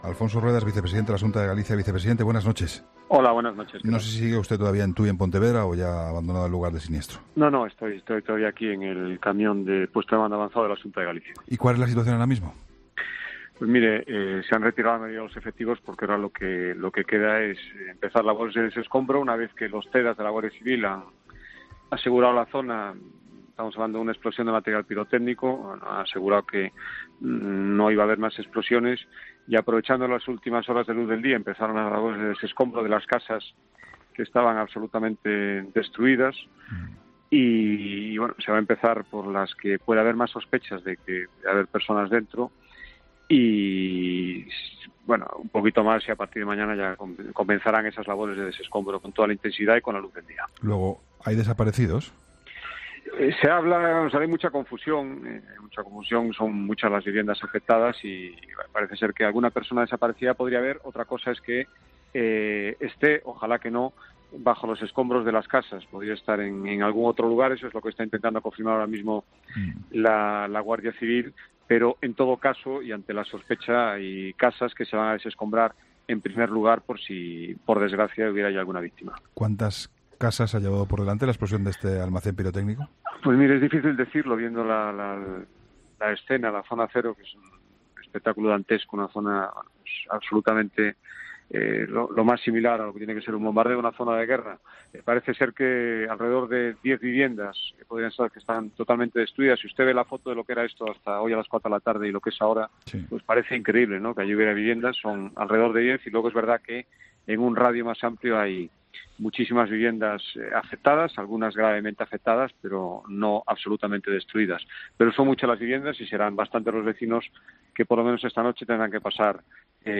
Entrevistas en La Linterna
El vicepresidente de la Xunta de Galicia, Alfonso Rueda, ha atendido a 'La Linterna' desde la localidad de Tui (Pontevedra) tras las explosión pirotécnica